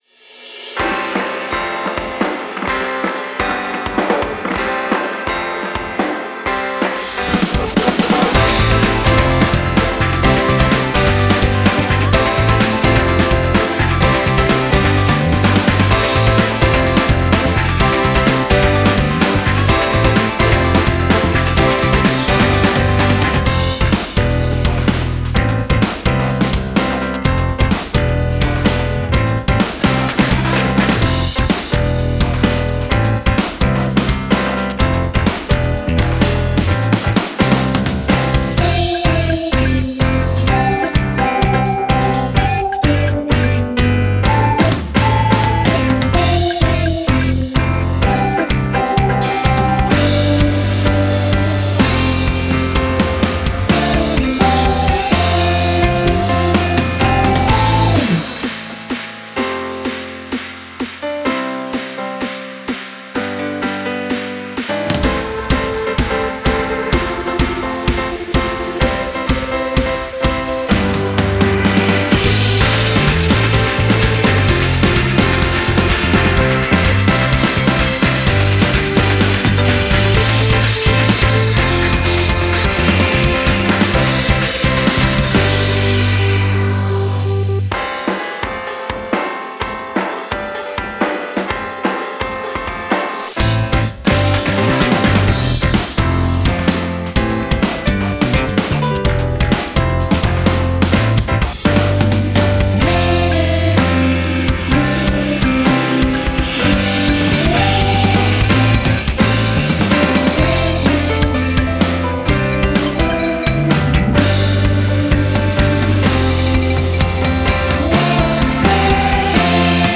オリジナルKey：「C